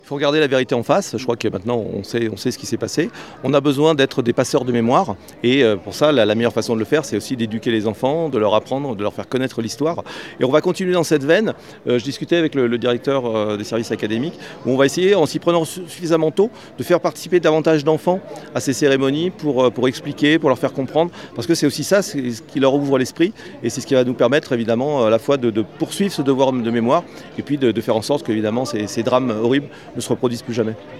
Cette stèle inaugurée est également un moyen de reconnaître l’implication de l’État français dans la traite négrière. Un devoir de mémoire pour ne pas oublier, explique le préfet.